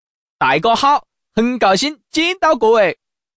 Index of /mahjong_xianlai/update/12943/res/sfx/changsha_man/